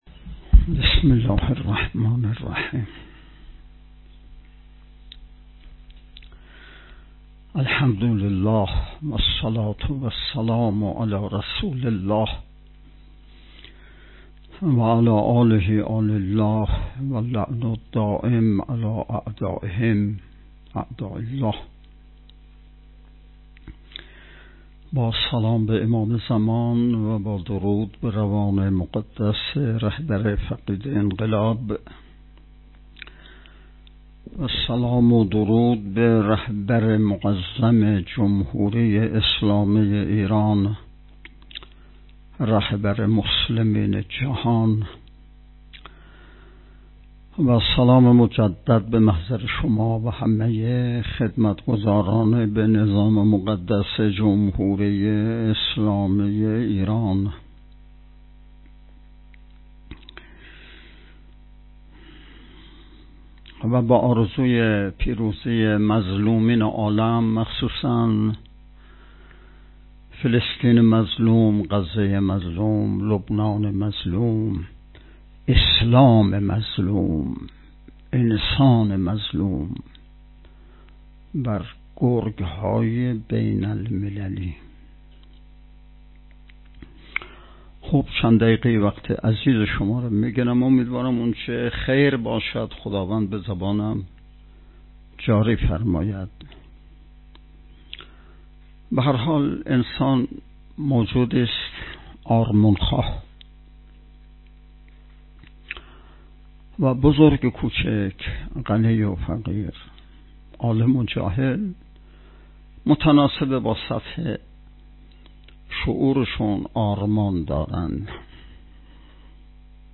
صوت / بیانات امام جمعه بیرجند در جلسه درس اخلاق مدیران دستگاه های اجرایی خراسان جنوبی
درس اخلاق مدیران دستگاه های اجرایی استان با سخنرانی حجت الاسلام والمسلمین سید علیرضا عبادی نماینده ولی فقیه در خراسان جنوبی و امام جمعه بیرجند امروز صبح ۲۶ شهریور برگزار شد.